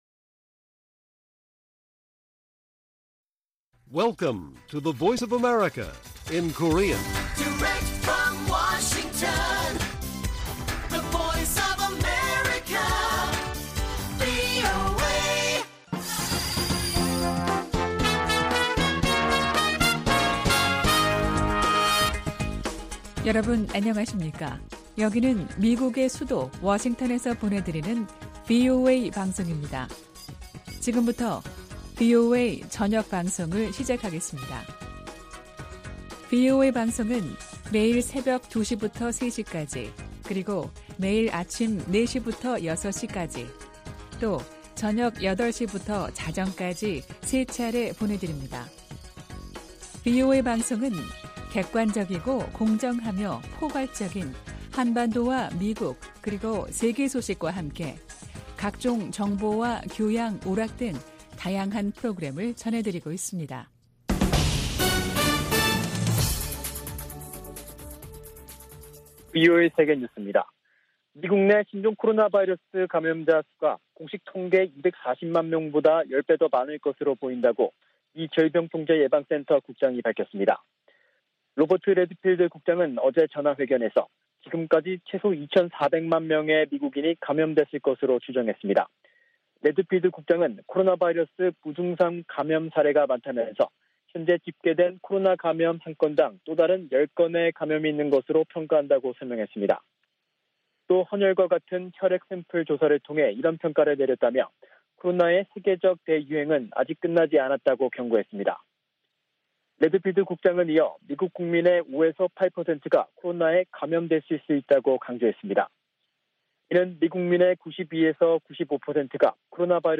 VOA 한국어 간판 뉴스 프로그램 '뉴스 투데이', 1부 방송입니다.